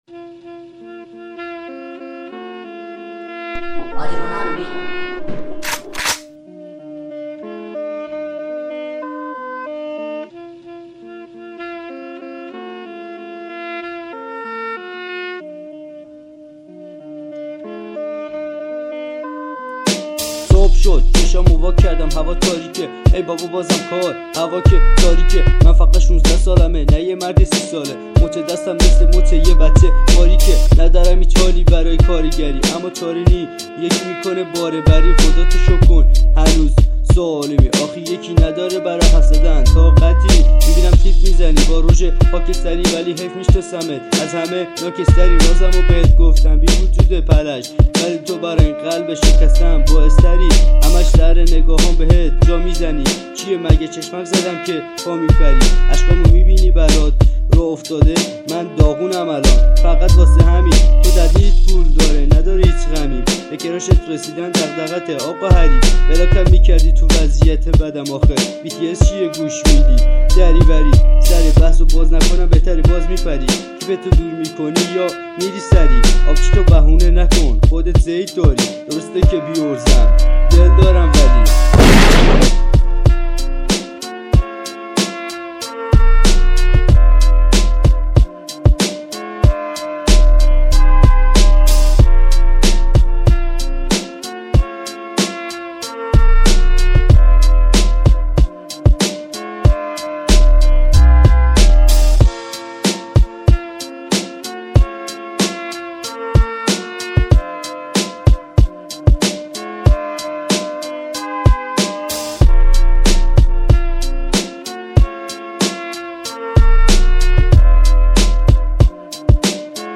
رپ هیپ هاپ غمگین